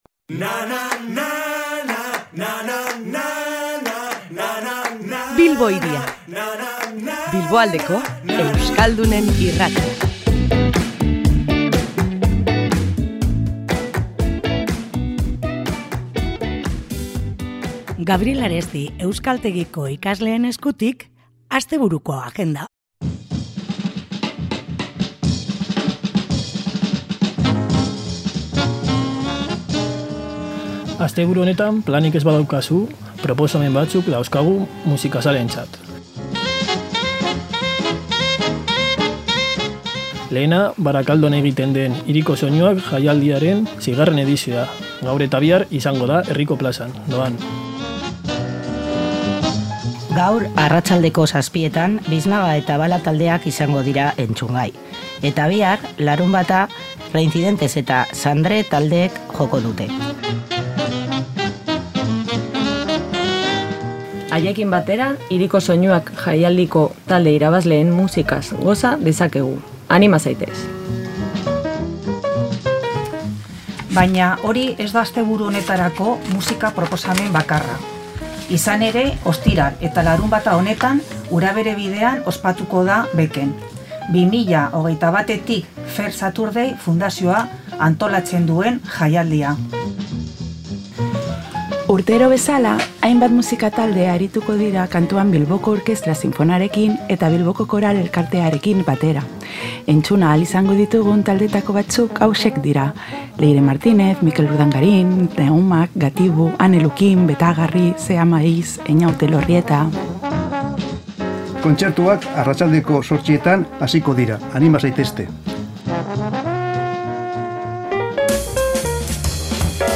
Gabriel Aresti euskaltegiko ikasleak izan ditugu gurean, eta astebururako planak ekarri dizkigute Bilbo Hiria irratira. Musika, antzerkia, zinema eta kultur ekitaldi ugari izango dira hirian datozen egunotan.